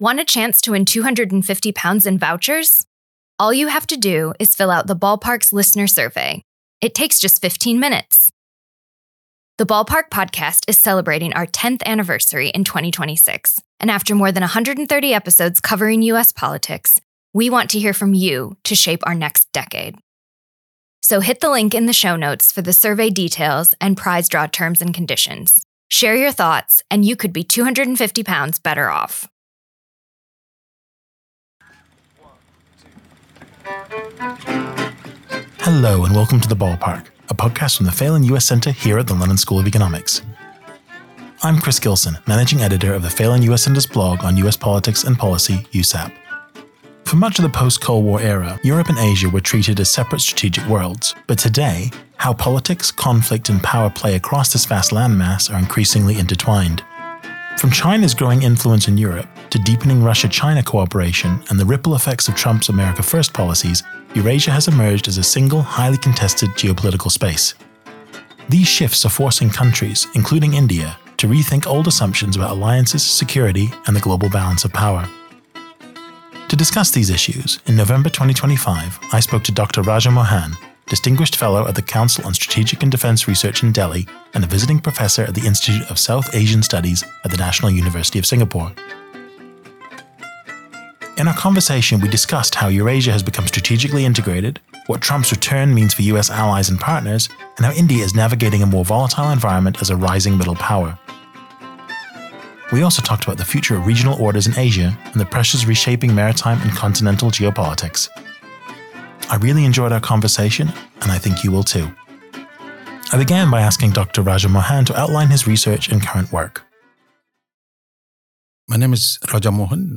Their conversation covers how Eurasia has become strategically integrated, what Trump’s return means for US allies and partners, and how India is navigating a more volatile environment as a rising middle power. They also discussed the future of regional orders in Asia and the pressures reshaping maritime and continental geopolitics.